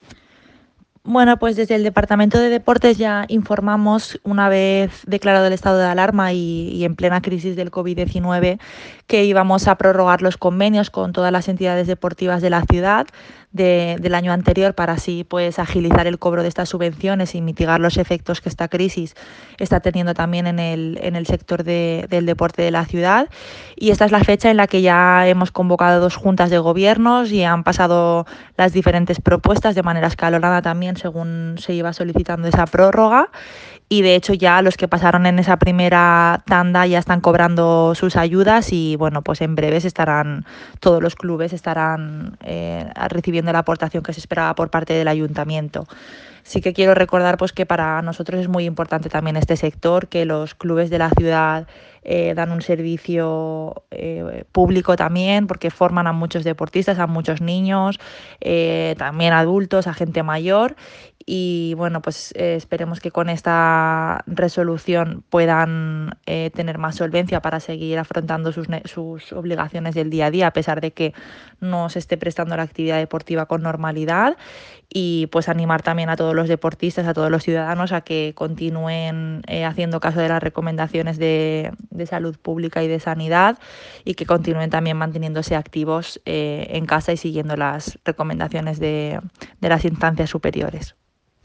lydia_declaracions.mp3